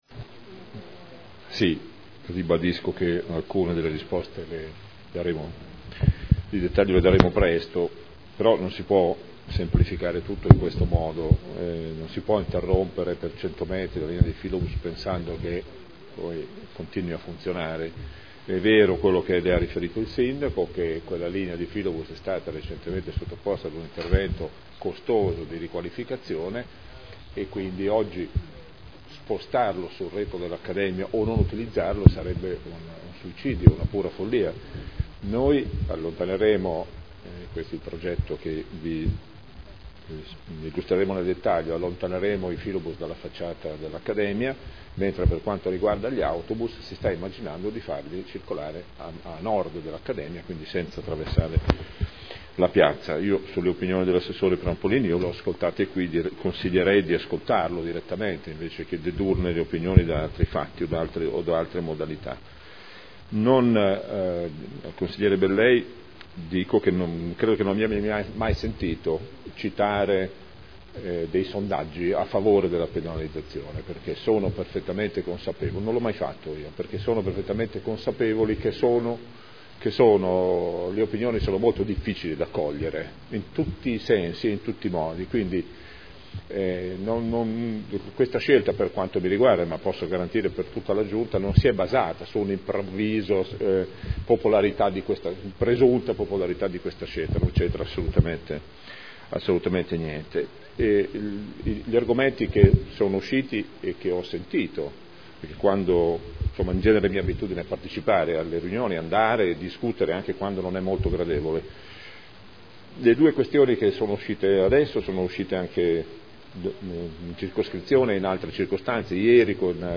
Gabriele Giacobazzi — Sito Audio Consiglio Comunale
Dibattito.